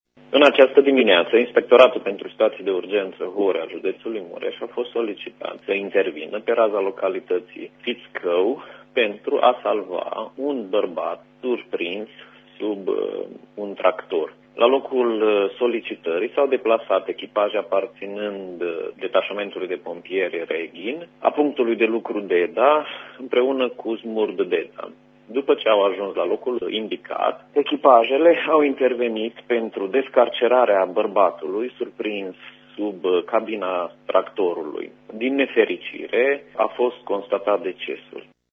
Radio România Mureș